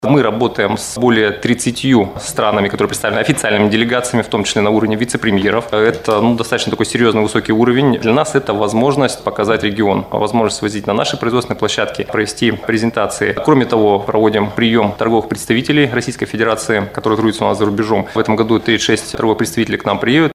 В выставке примут участие представители более 50 стран, — рассказал заместитель министра международных связей региона Олег Александрин.